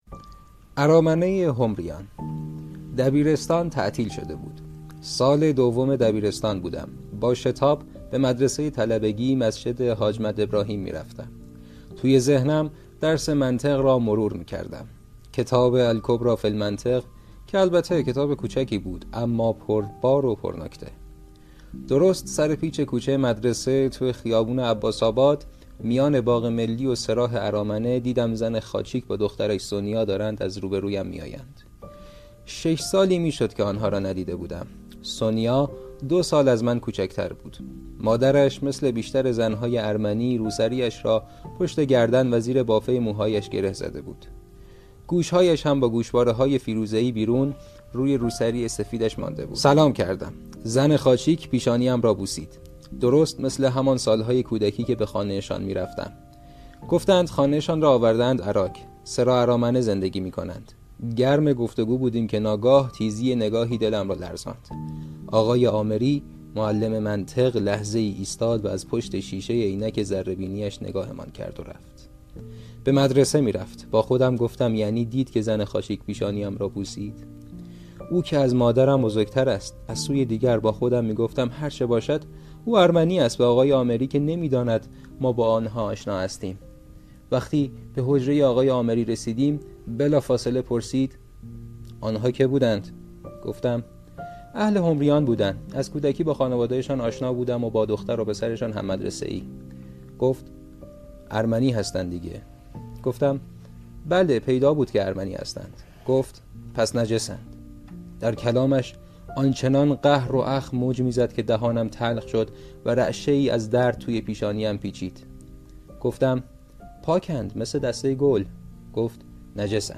خوانش: